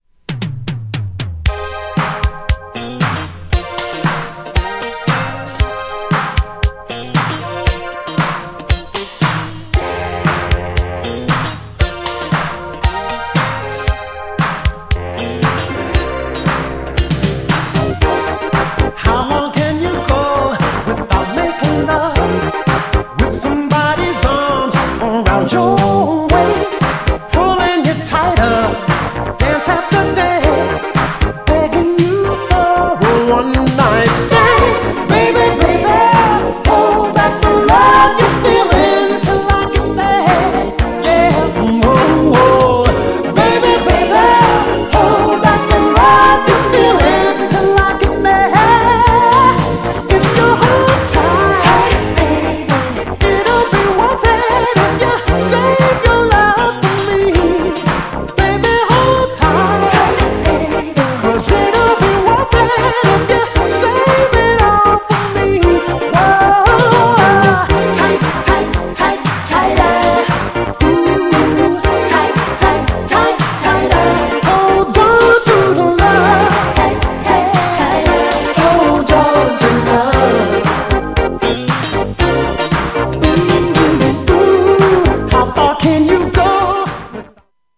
キーワードはアーバン、コンテンポラリー!!
B面はダンサブルな曲でこちらもすっごく良いです!!
GENRE Dance Classic
BPM 76〜80BPM
# アフターアワーズ # アーバン # エモーショナル # フリーソウル # ブラコン # ミディアム # 切ない感じ